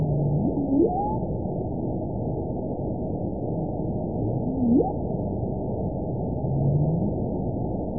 event 919353 date 12/31/23 time 05:38:55 GMT (1 year, 5 months ago) score 9.00 location TSS-AB05 detected by nrw target species NRW annotations +NRW Spectrogram: Frequency (kHz) vs. Time (s) audio not available .wav